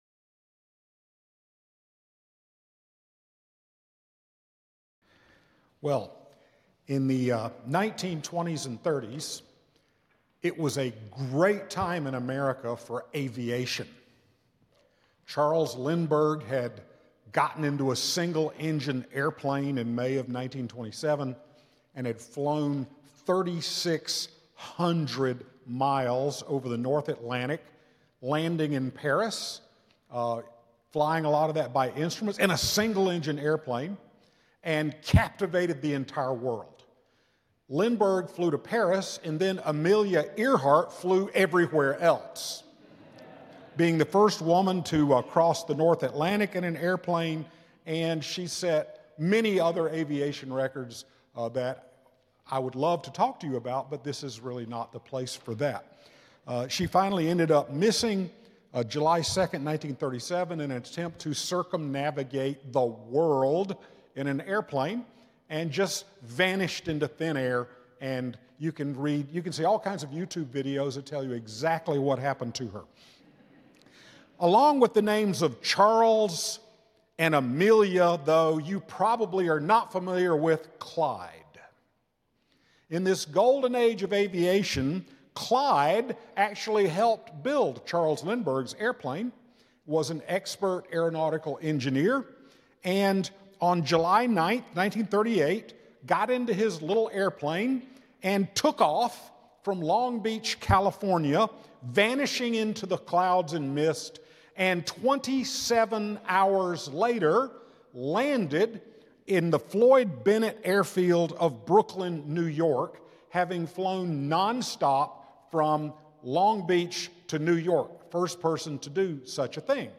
The following service took place on Tuesday, April 22, 2025.
Sermon